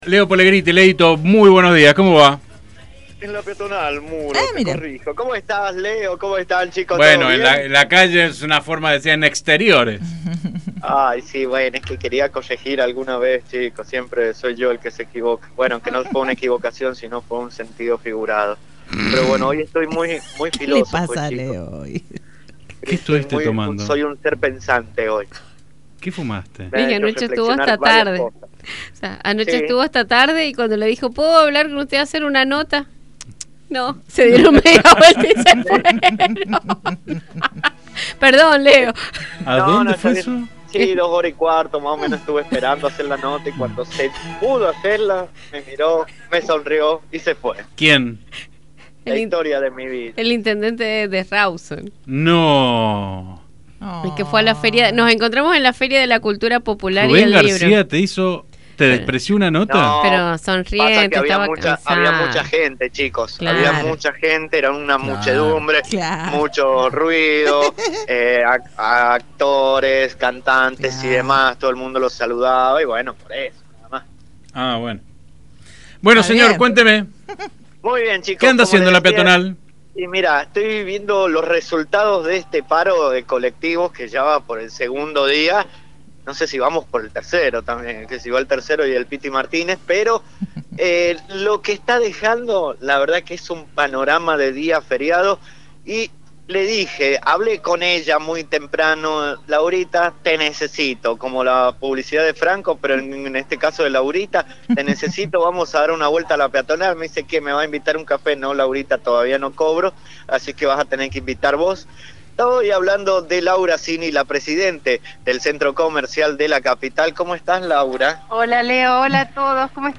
dialogó con los periodistas de Radio Sarmiento